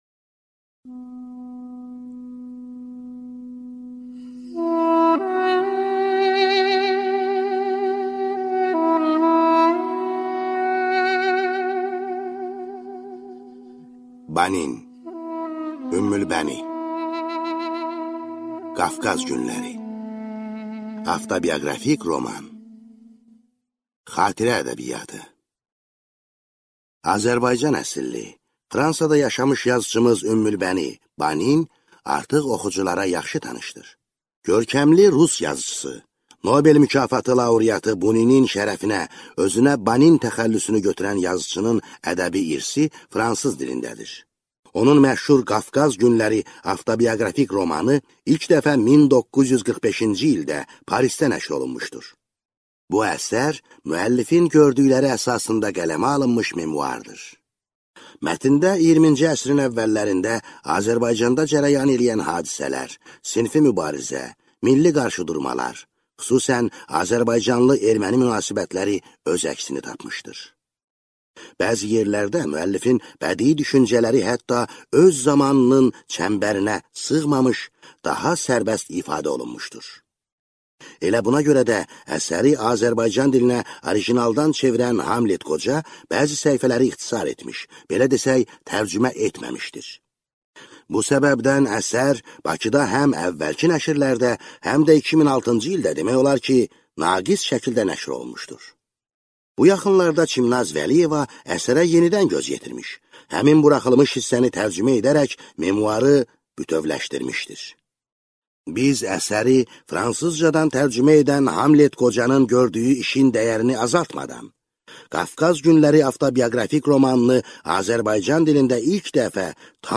Aудиокнига Qafqaz günləri Автор Банин